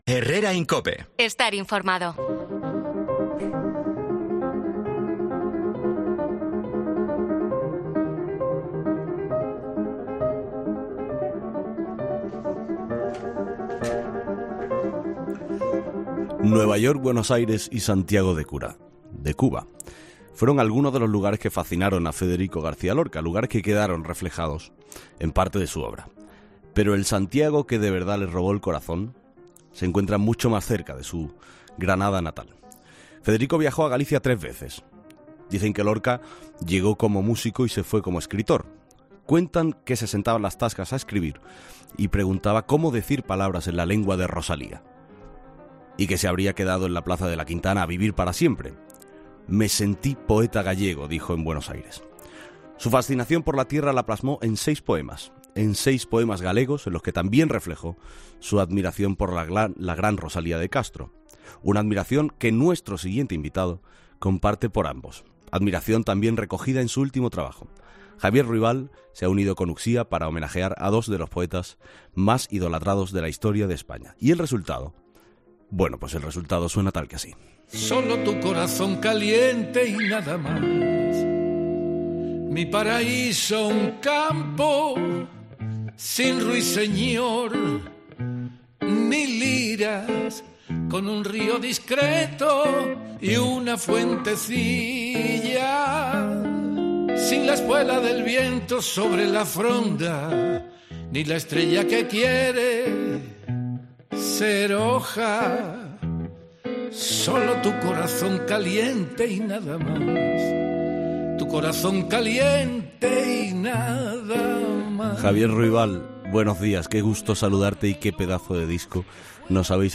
AUDIO: El cantaor se ha pasado por 'Herrera en COPE' para hablar de su nuevo disco con Uxía, "De tu casa a la mía"